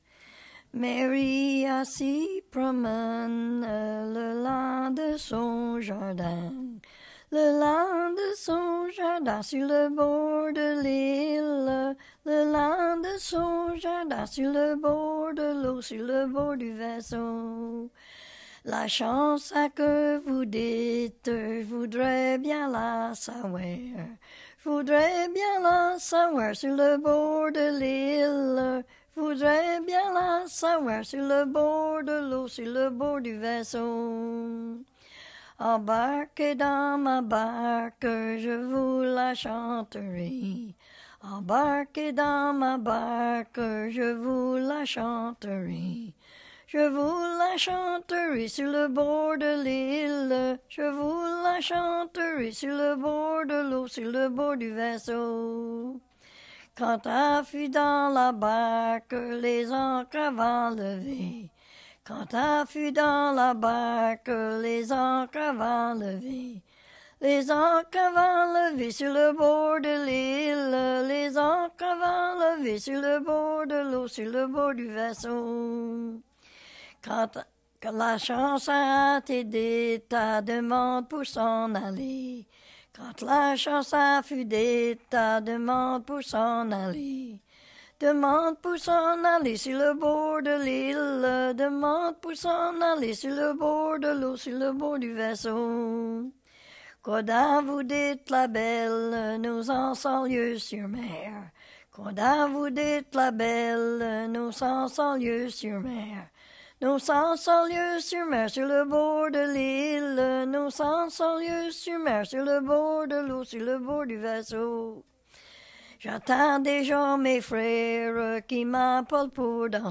Chanson Item Type Metadata
Emplacement Upper Ferry